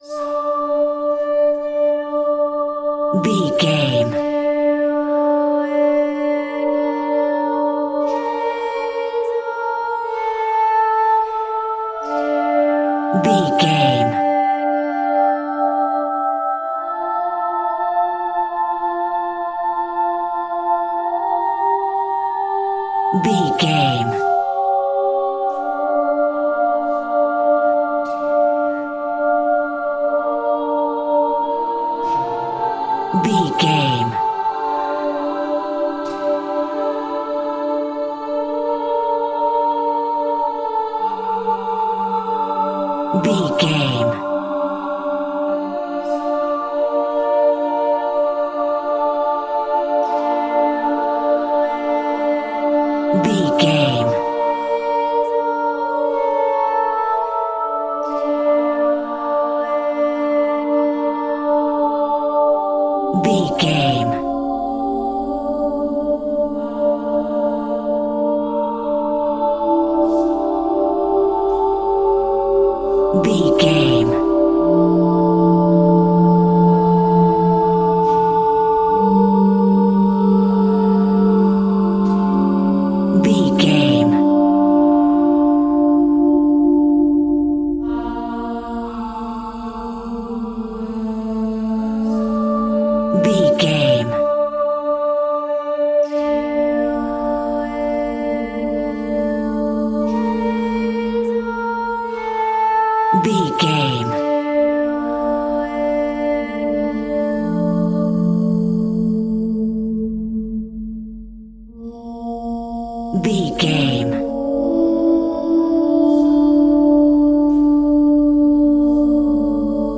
Aeolian/Minor
D
scary
tension
ominous
dark
suspense
eerie
vocals
synthesizer
horror
Eerie Voices